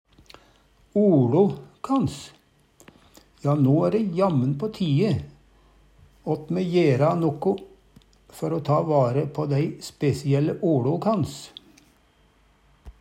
oLo kans dialekta vår, orda våre Eksempel på bruk Ja, no æ ræ jammen på tie åt me jera noko før o ta vare på dei spesielle oLo kans. Hør på dette ordet Ordklasse: Uttrykk Kategori: Kropp, helse, slekt (mennesket) Attende til søk